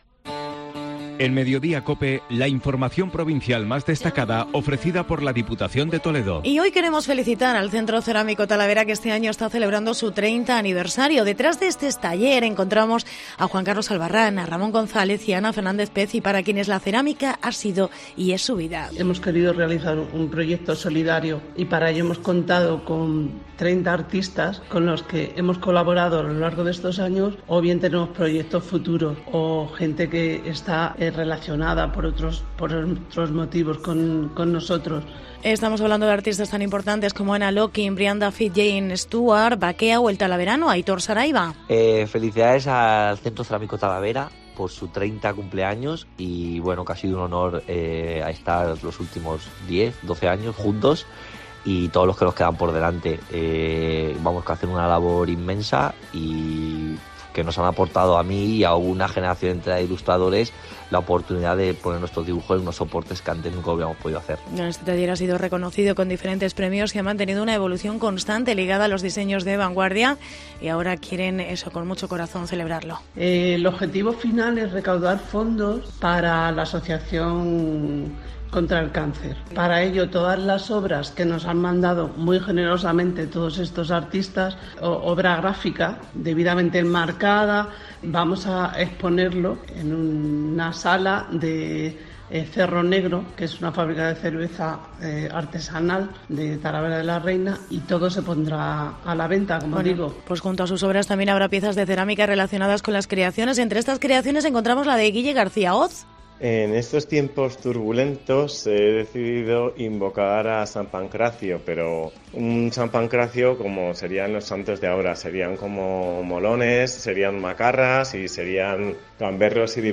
Reportaje 30 aniversario Centro Cerámico Talavera